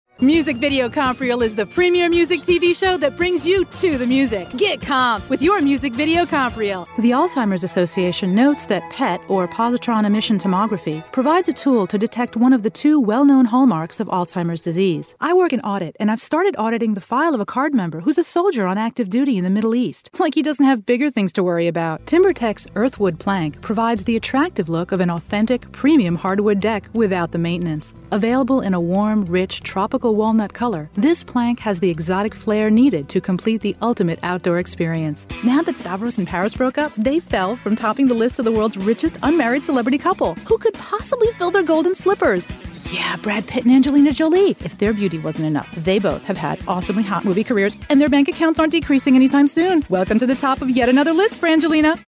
Sprechprobe: Werbung (Muttersprache):
English and French Female Voice over artist for phone systems, corporate videos, radio and TV spots